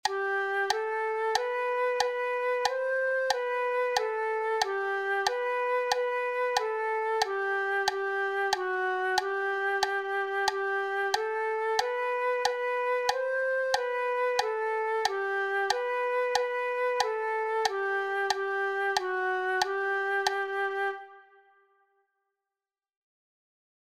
Branle_A_Andante.mp3